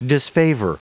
Prononciation du mot disfavor en anglais (fichier audio)
Prononciation du mot : disfavor